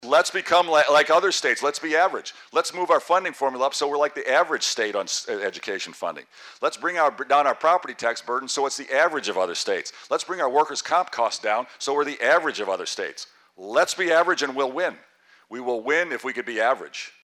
Rauner spoke to QC Chamber of Commerce members Tuesday in Rock Island.